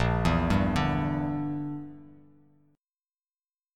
Bb6b5 chord